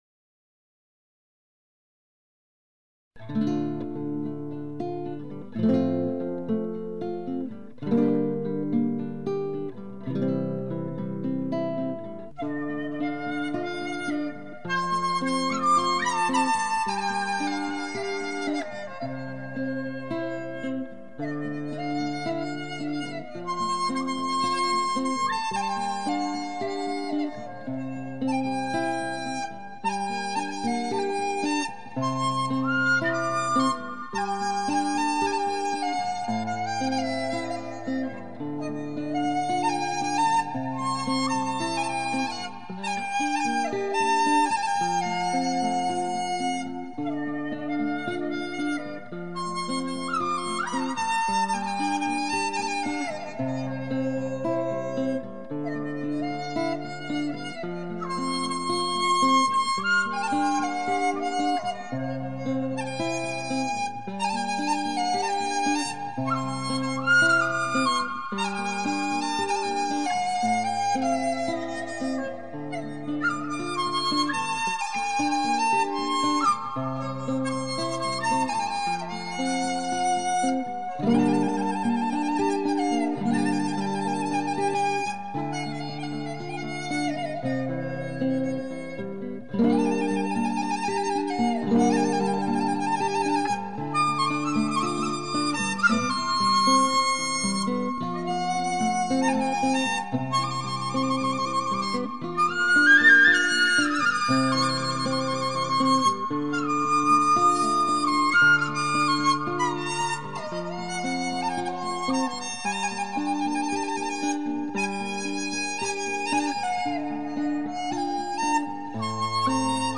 這個合奏集錦包括笛子與葫蘆絲、葫蘆絲二重奏、笛子與吉他的合奏。